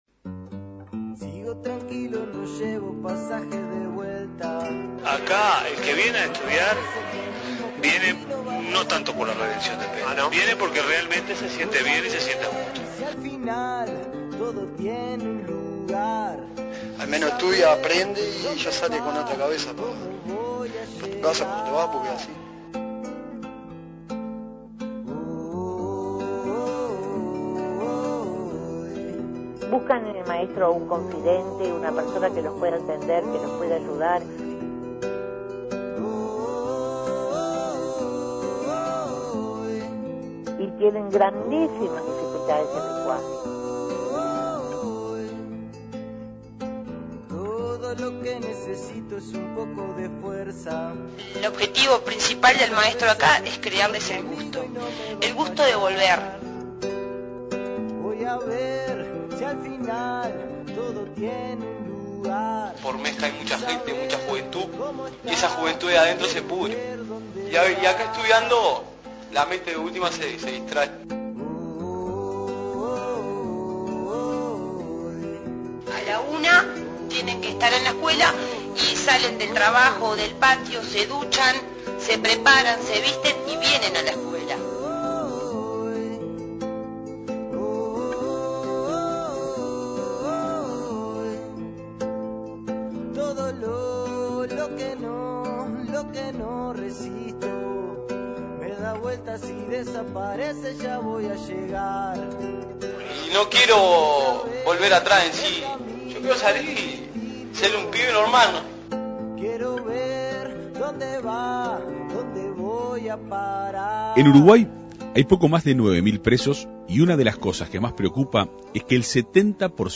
El estudio durante el período de reclusión es la apuesta más importante para revertir este proceso. Escuche el informe de la Segunda Mañana de En Perspectiva.